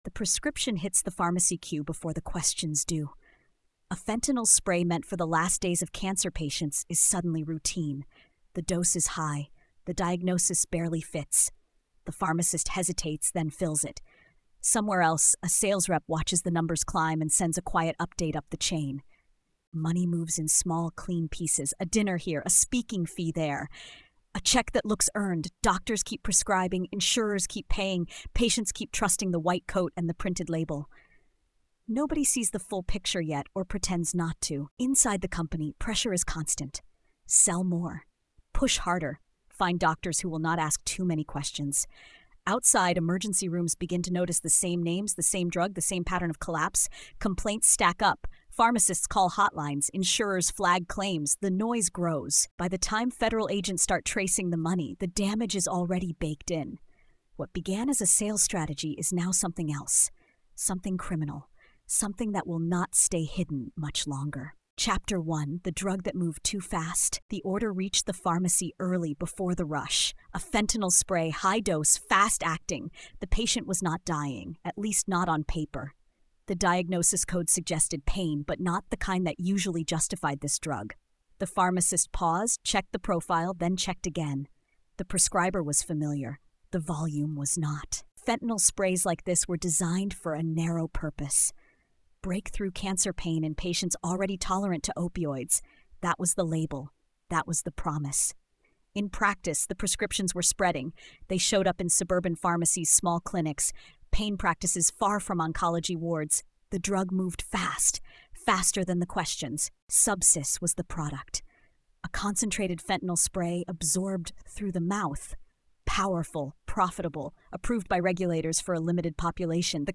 Pushing the Pain is a gritty nonfiction forensic series that traces how a powerful fentanyl spray moved from a narrow medical purpose into widespread misuse through corporate pressure, financial incentives, and compromised judgment. Told with restraint and urgency, the story follows the path from sales targets and speaker fees to pharmacies, emergency rooms, and courtrooms, exposing how a system designed to manage pain instead amplified harm.